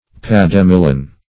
pademelon \pad`e*mel"on\ n. (Zool.)